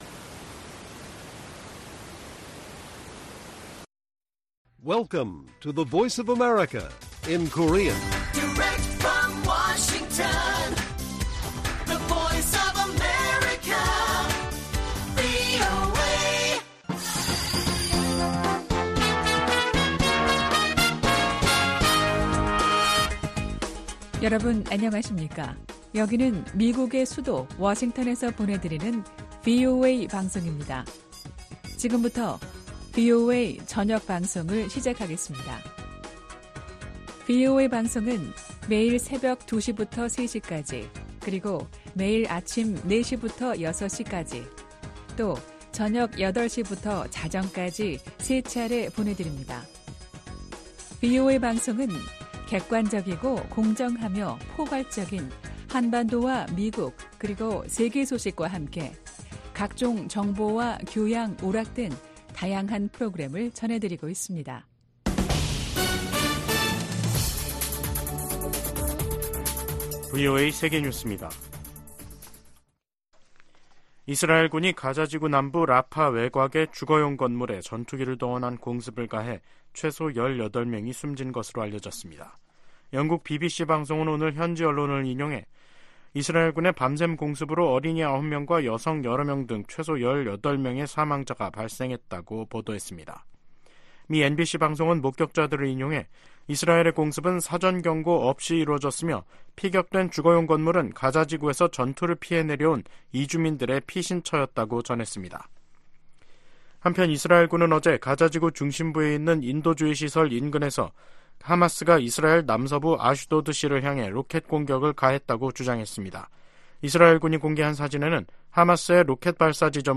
VOA 한국어 간판 뉴스 프로그램 '뉴스 투데이', 2024년 3월 26일 1부 방송입니다. 조 바이든 미국 대통령이 서명한 2024회계연도 예산안에 북한 관련 지출은 인권 증진, 대북 방송, 북한 내 미군 유해 관련 활동이 포함됐습니다. 미 국방부가 일본 자위대의 통합작전사령부 창설 계획에 대한 지지를 표명했습니다. 남북한이 다음달 군사정찰위성 2호기를 쏠 예정으로, 우주경쟁에 돌입하는 양상입니다.